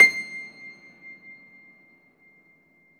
53a-pno22-C5.wav